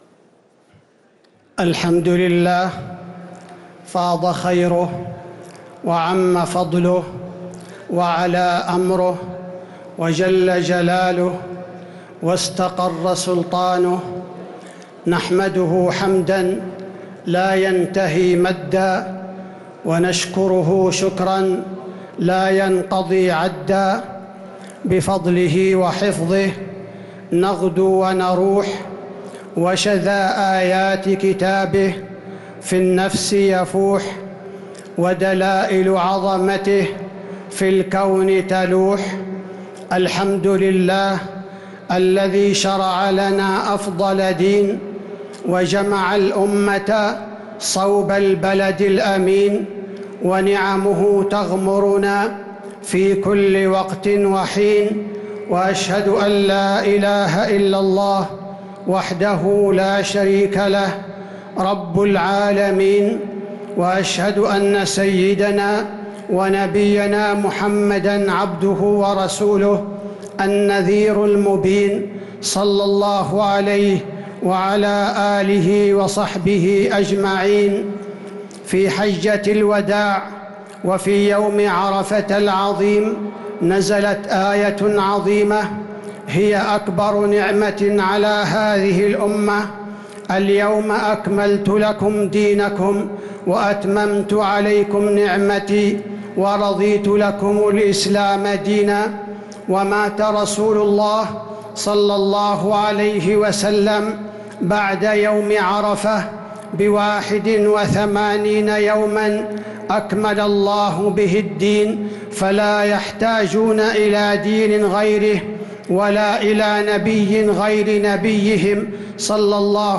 خطبة عيد الأضحى المبارك 1443هـ | khutbat Eid al Adha 9-7-2022 > خطب الحرم النبوي عام 1443 🕌 > خطب الحرم النبوي 🕌 > المزيد - تلاوات الحرمين